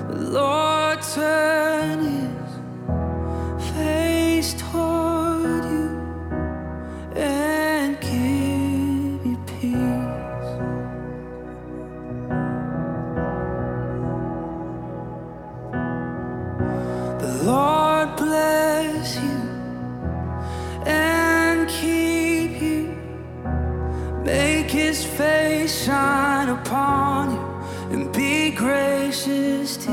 • Christian & Gospel